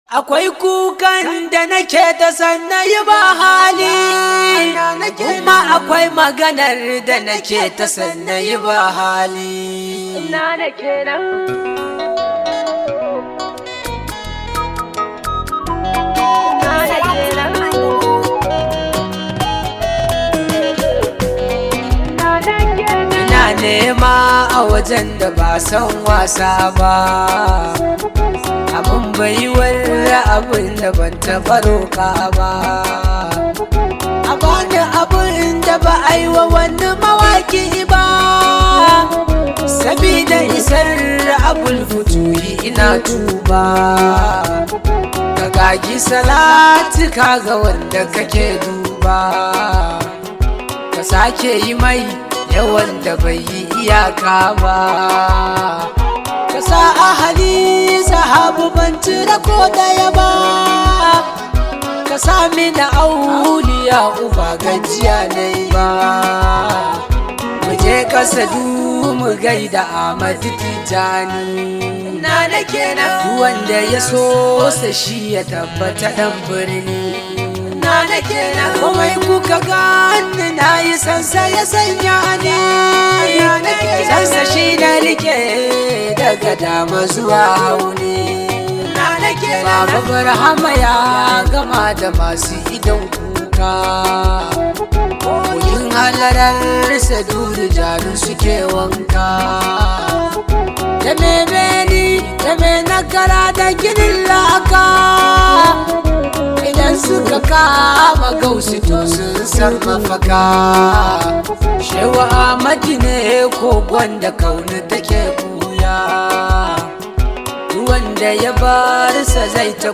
hausa song
This high vibe hausa song